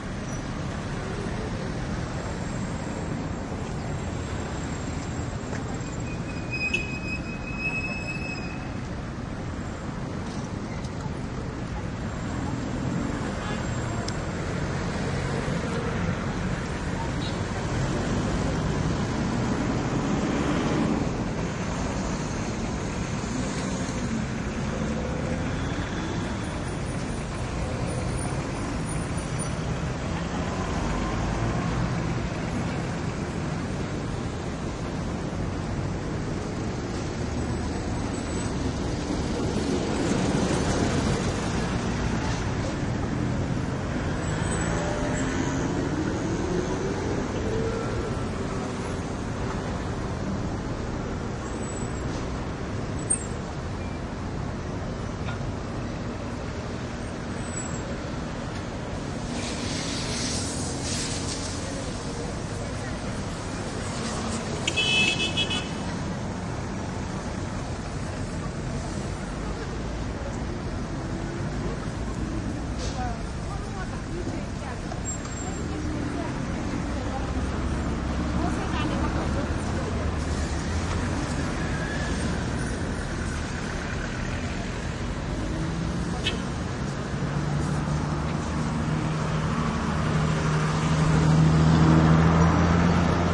印度 " 交通繁忙 接近柔软的摩托车轻便摩托车的人与遥远的Ganpati鼓手1 印度
描述：交通重型关闭软摩托车轻便摩托车与遥远的Ganpati鼓手1 India.flac
标签： 关闭 交通 遥远 轻便摩托车 鼓手 Ganpati 摩托车 印度
声道立体声